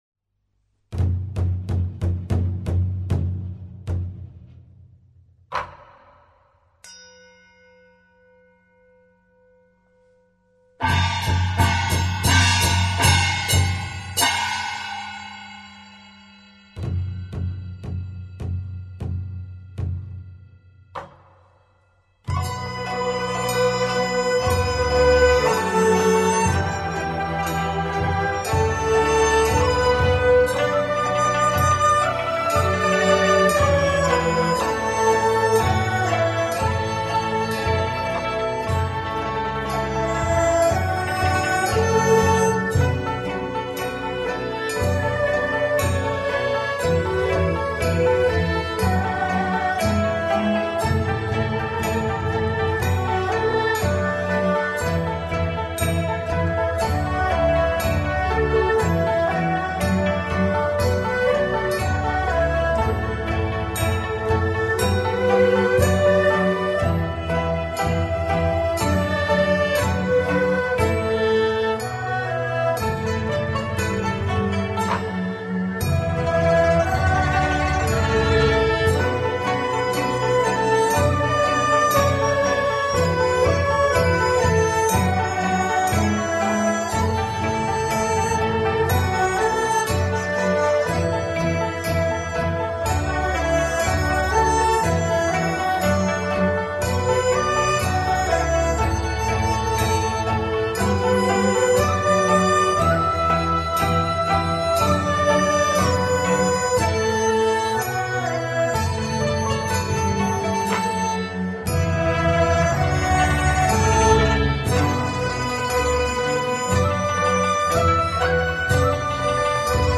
上海道教音乐是一个各乐种成分相互融汇、并具有江南独特音乐风格和丰富道教色彩的音乐。它伴随着上海道教浩繁的斋醮科仪的进行，灵活巧妙地在各种场合穿插运用，有时鼓声震天，气势磅礴，以示召神遣将、镇邪驱魔；有时丝竹雅奏，余音绕梁，使人身心清静，如入缥缈之境。